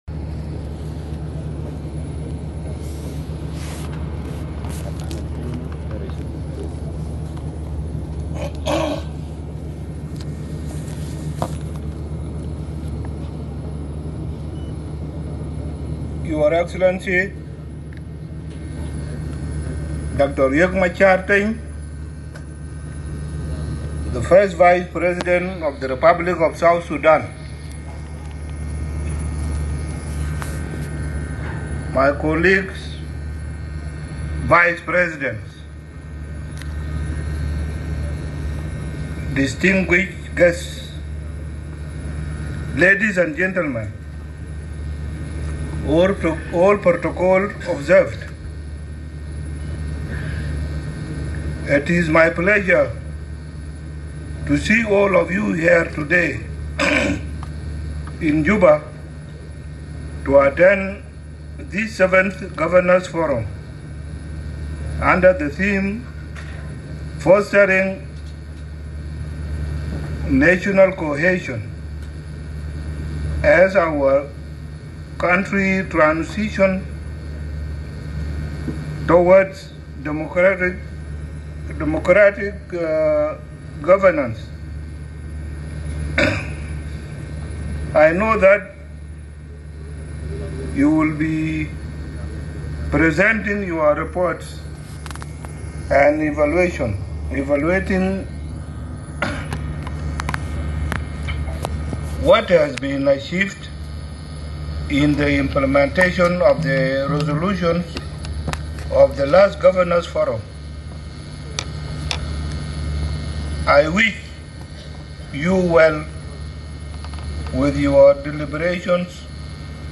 President Salva Kiir's remarks at the opening of the 7th Governors' Forum in Juba